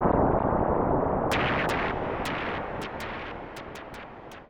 Ambient
1 channel